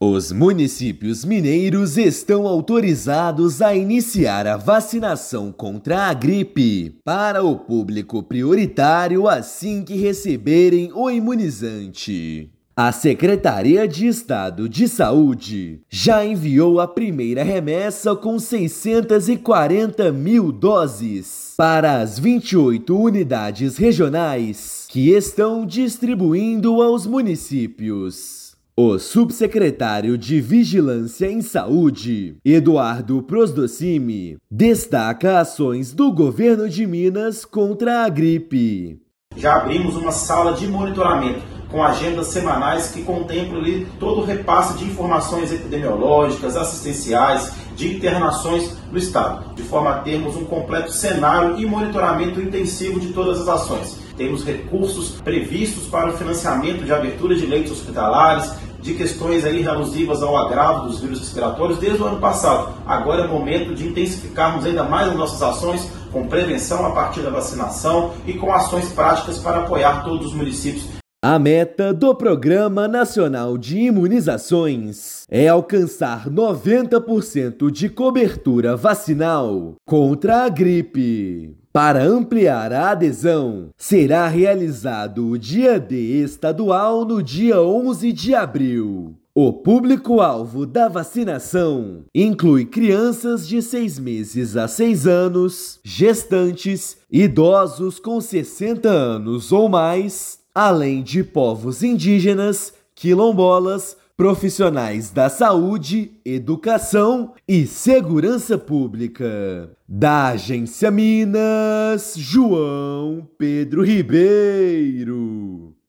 [RÁDIO] Minas antecipa vacinação contra a gripe e amplia a proteção em todo o estado
Primeira remessa com 640 mil doses já está em distribuição no estado e terá Dia D em 11/4. Ouça matéria de rádio.